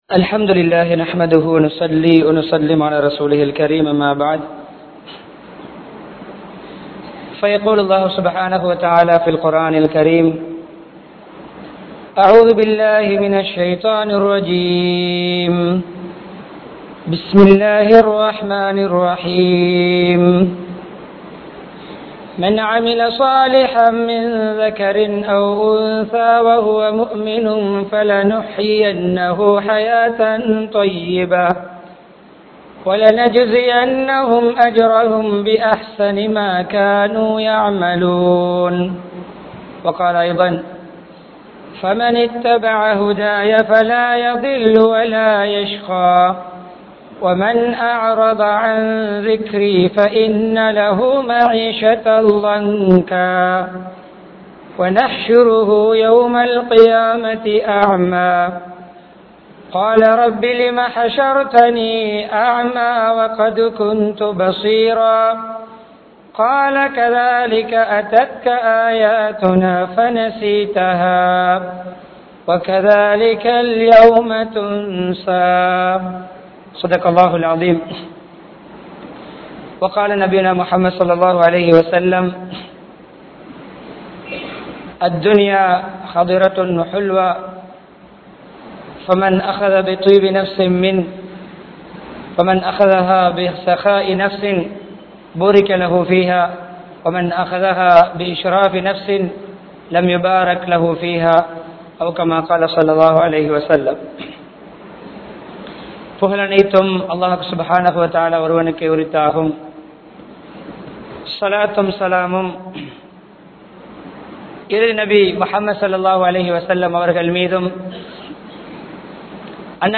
02 Vahaiyana Vaalkai Murai (02 வகையான வாழ்க்கை முறை) | Audio Bayans | All Ceylon Muslim Youth Community | Addalaichenai